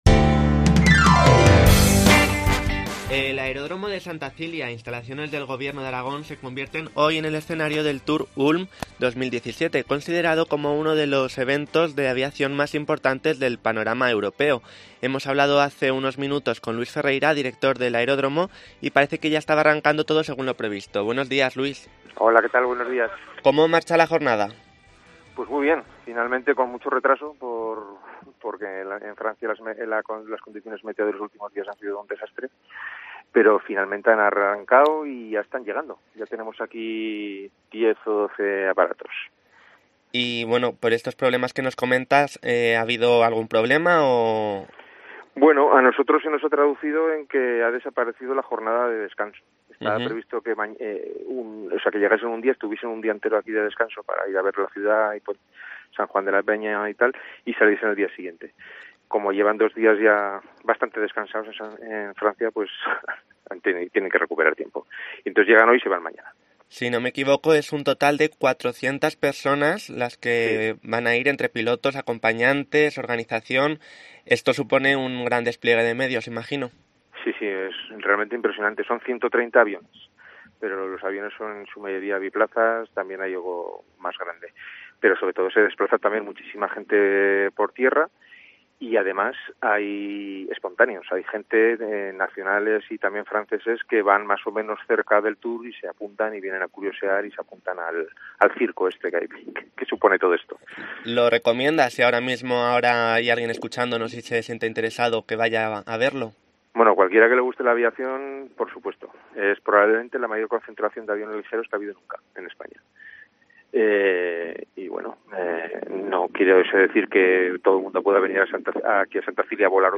Entrevista Santa Cilia por TOUR ULM 2017